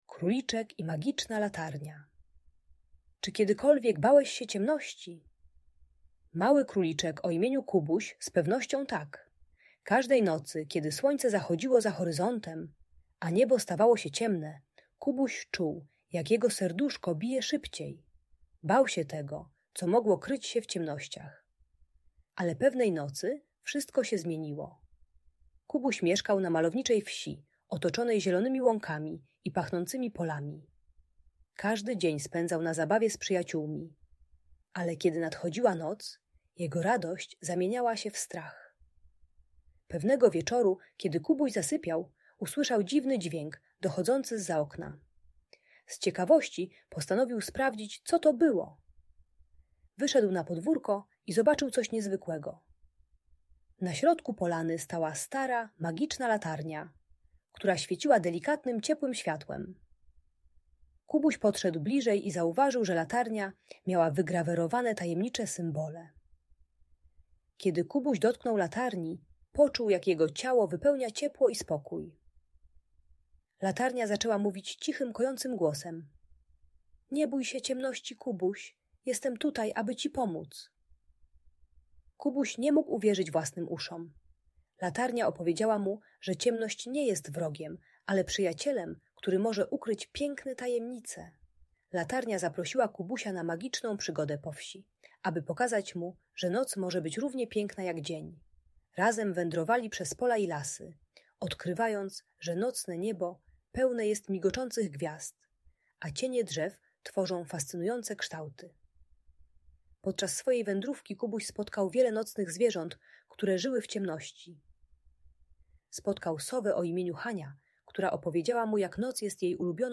Króliczek i magiczna latarnia - historia o przezwyciężaniu strachu - Audiobajka